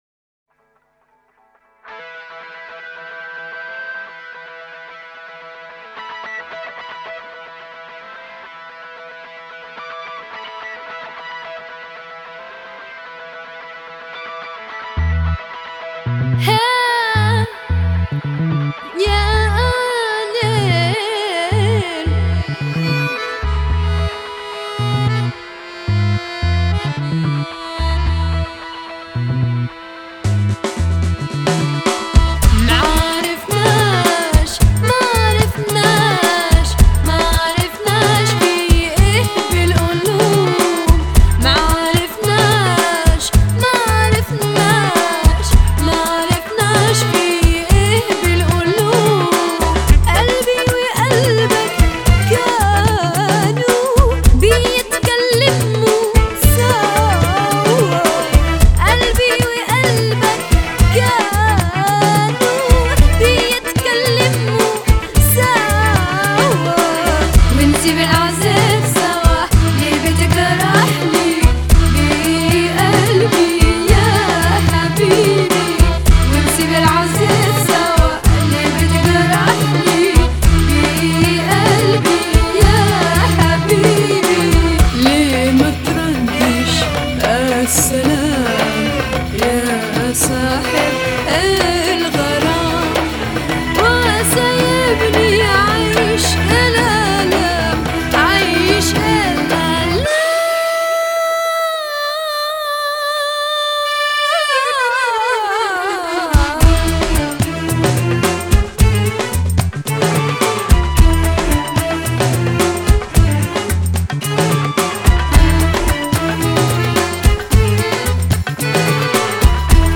اغنية عربية